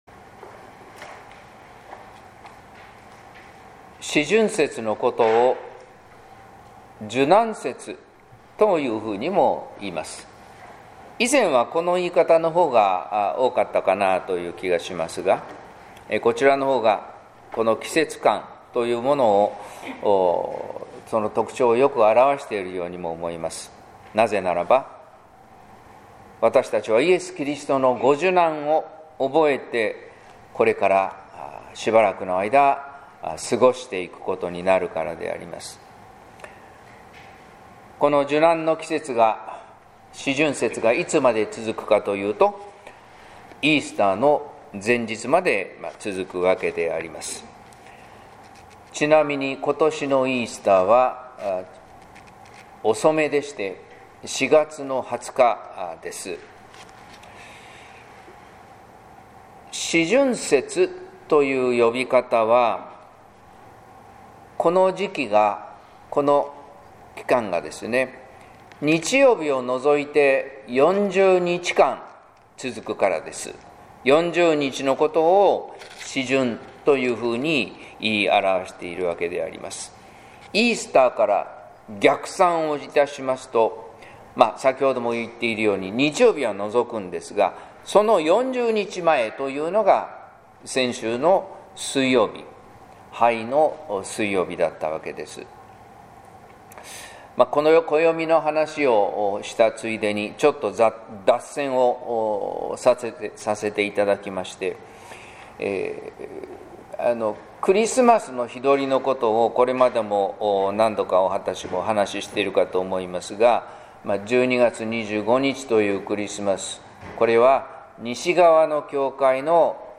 説教「石がパンになってたら」（音声版）